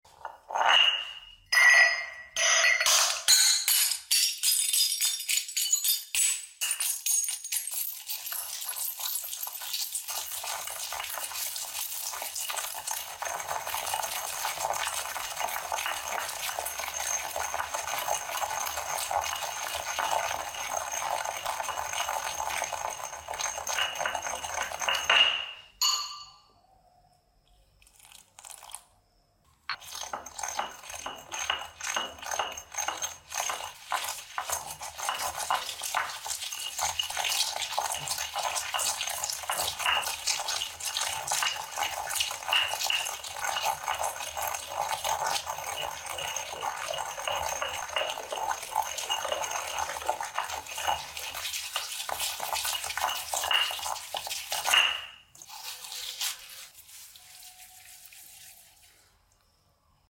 Upload By ASMR videos
Oddlysatisfying crushing blue Mug into